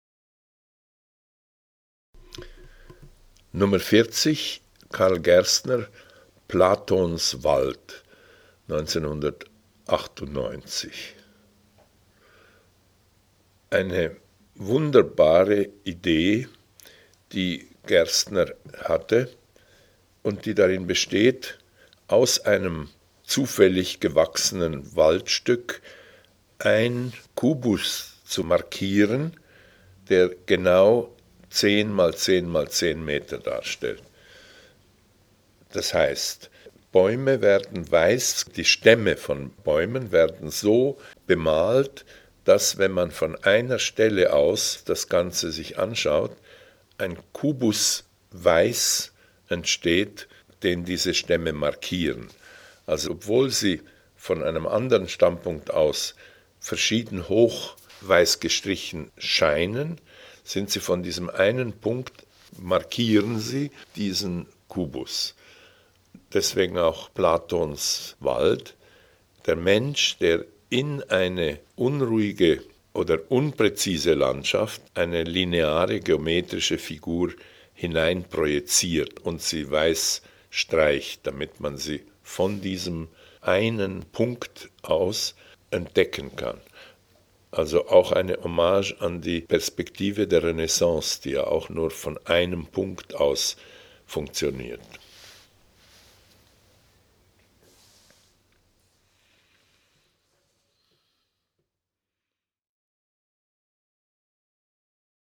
audioguide_40_gerstner_wald_giardino-daniel-spoerri.mp3